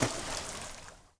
Water / Splashes
SPLASH_Subtle_04_mono.wav